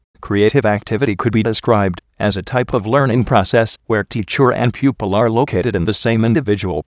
Location: USA